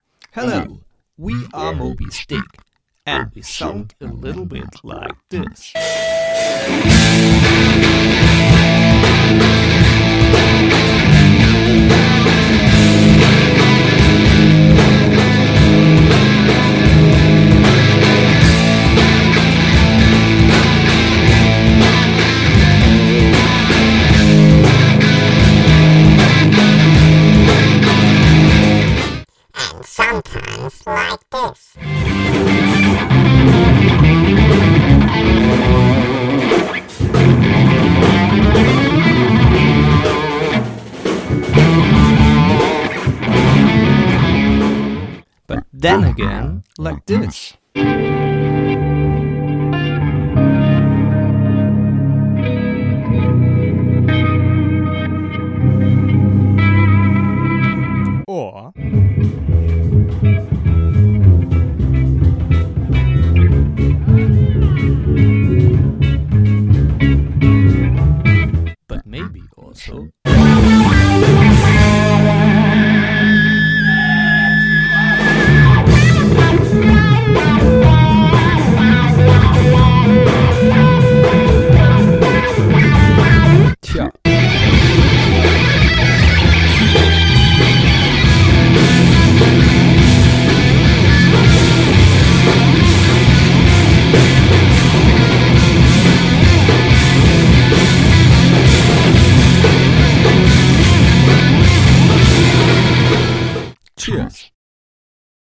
Music Preview / Snippets (live recordings)